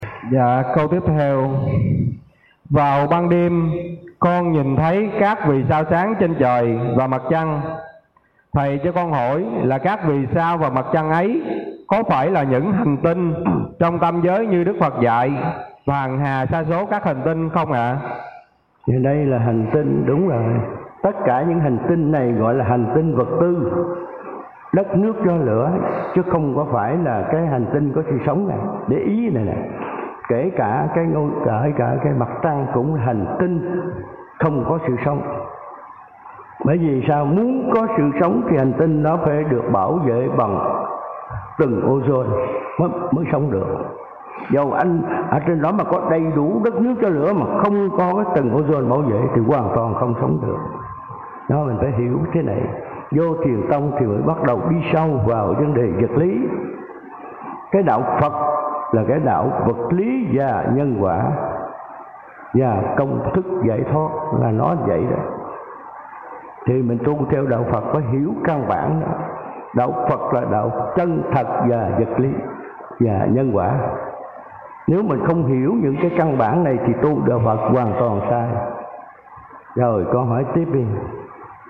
Trò hỏi:
Thầy trả lời: